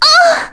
Reina-Vox_Damage_kr_02.wav